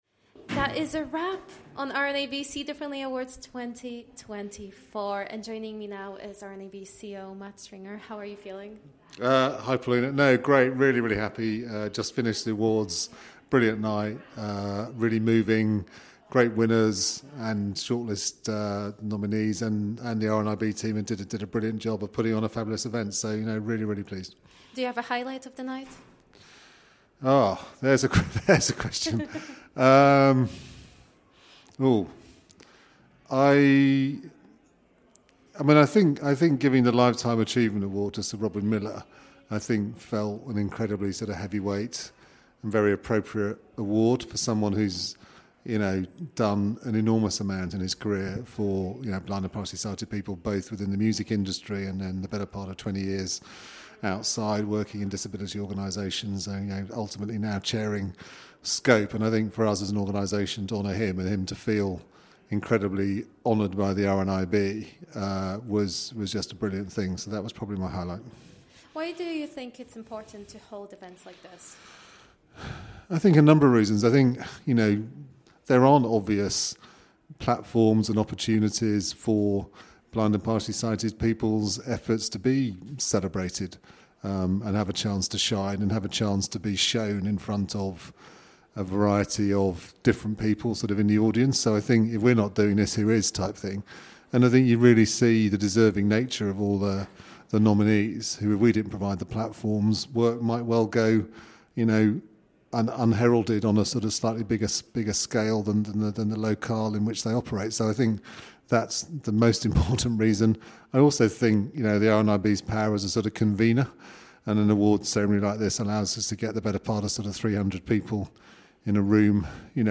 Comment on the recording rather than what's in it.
The RNIB See Differently Awards 2024 supported by the People’s Postcode Lottery winners took place in London on Tuesday 21st of May.